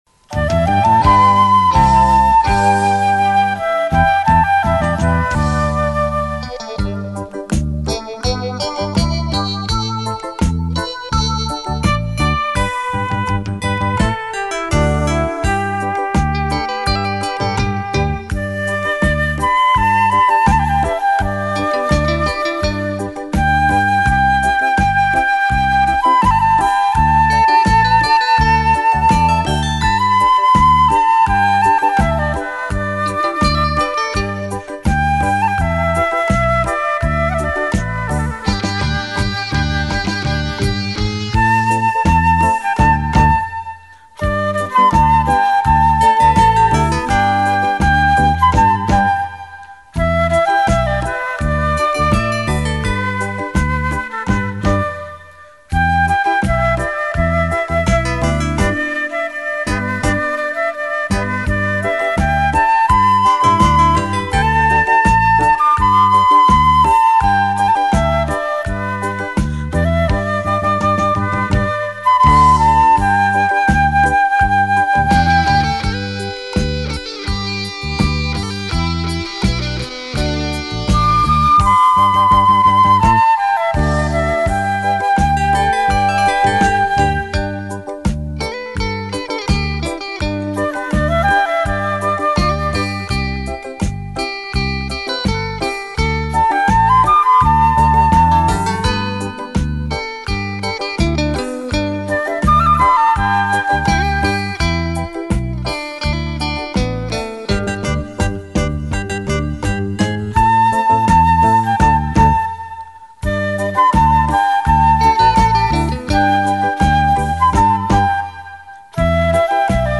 尺八洞箫优柔温润，曲风悠扬